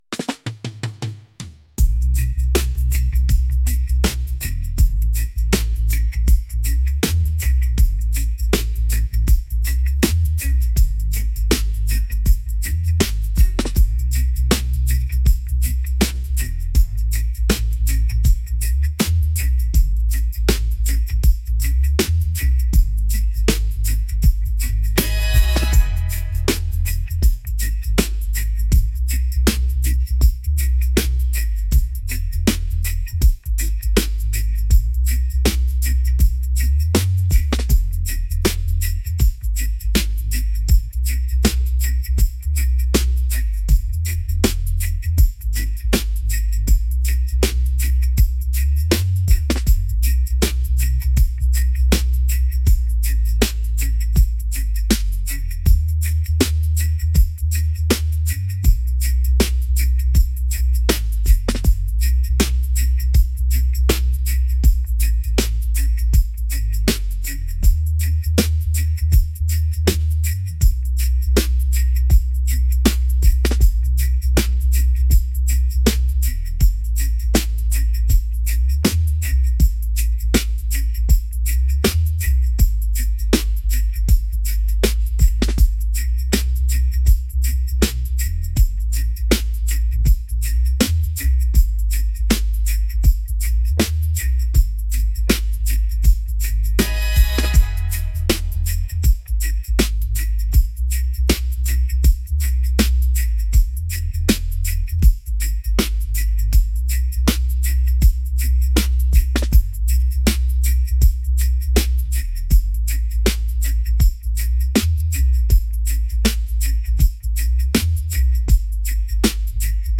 reggae | upbeat